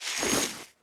equip_chain6.ogg